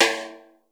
TIMB.SNR.wav